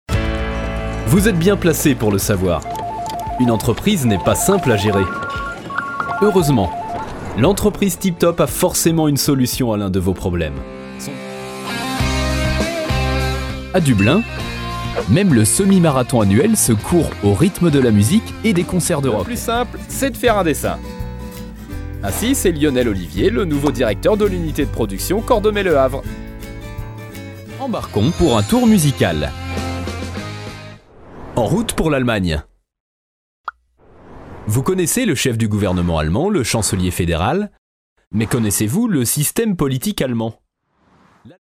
Native speaker Male 30-50 lat